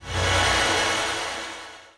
snd_ui_newmountget.wav